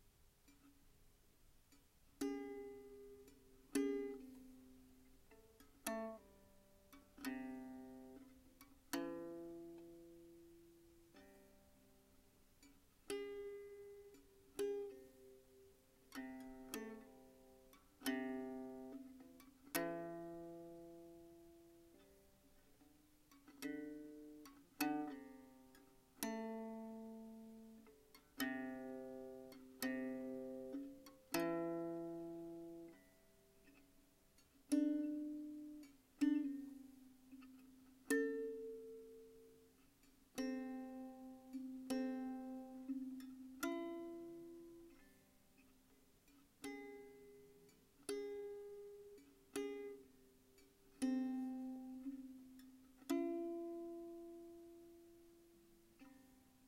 三味線